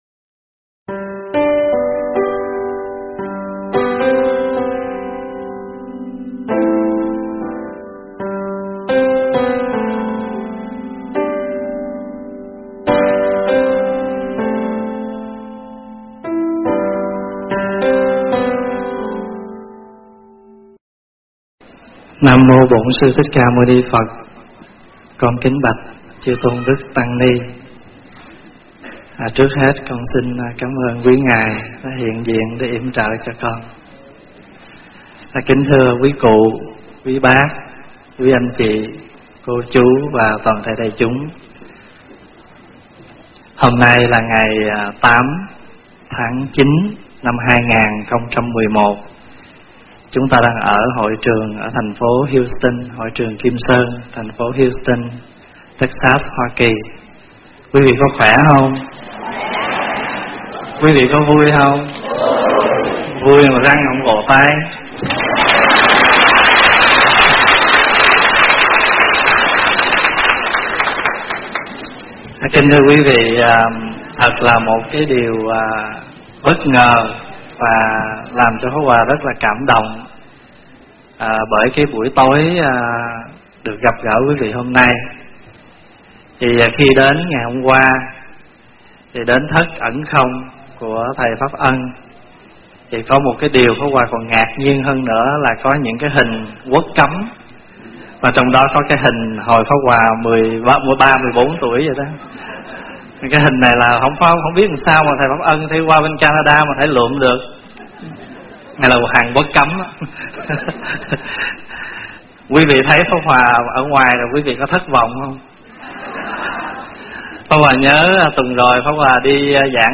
Mp3 thuyết pháp Lời Hoa - ĐĐ. Thích Pháp Hòa
Mời quý phật tử nghe mp3 thuyết pháp Lời Hoa do ĐĐ. Thích Pháp Hòa giảng